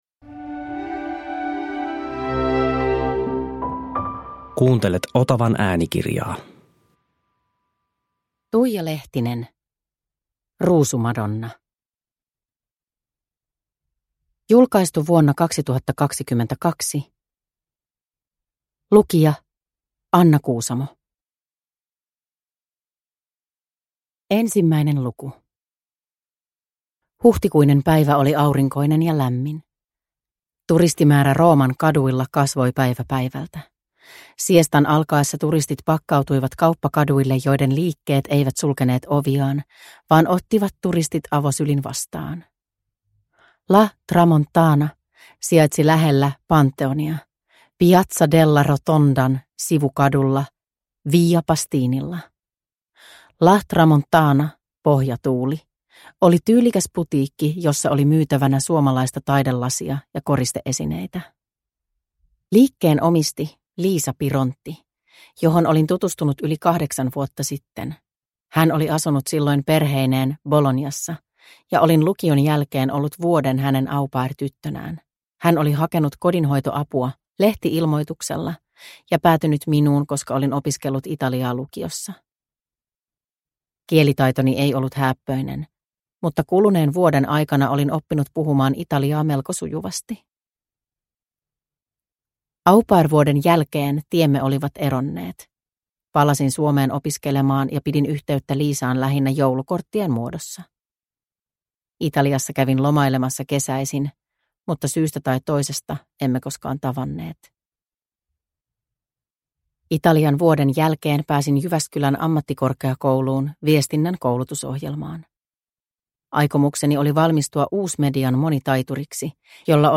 Ruusumadonna – Ljudbok – Laddas ner